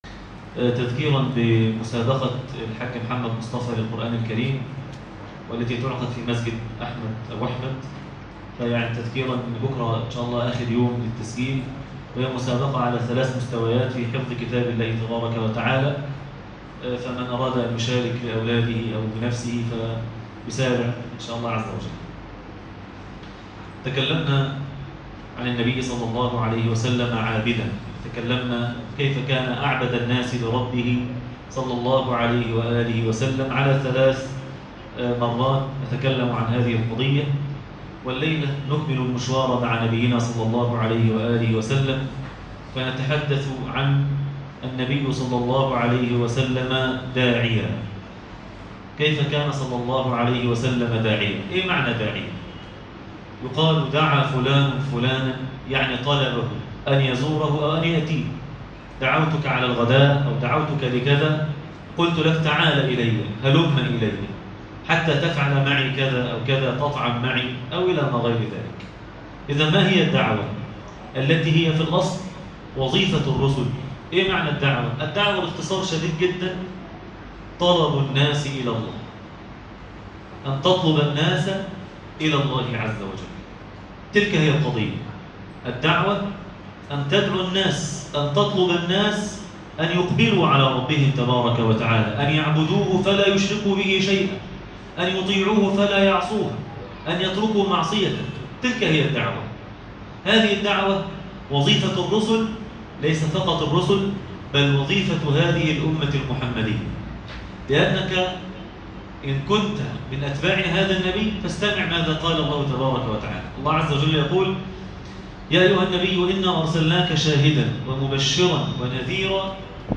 النبي (صلي الله عليه وسلم ) داعيا - درس التراويح ليلة 6 رمضان 1437 هـ